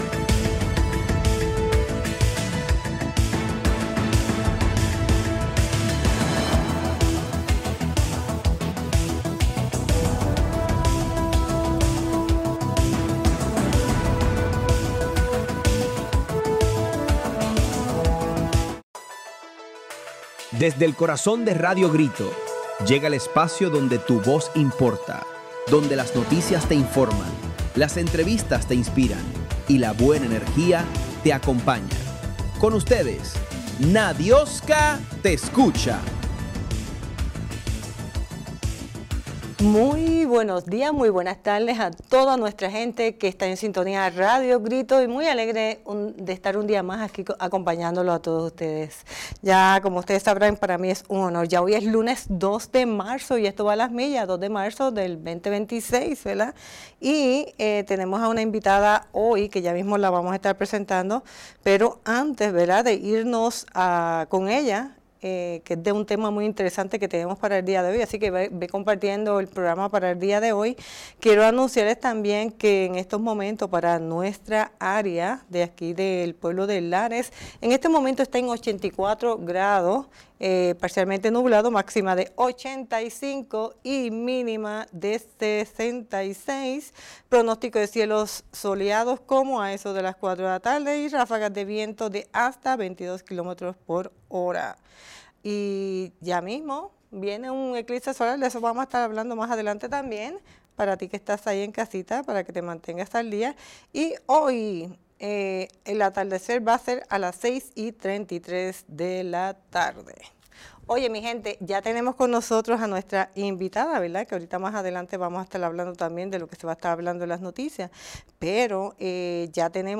Una entrevista necesaria, informativa y llena de herramientas para proteger vidas.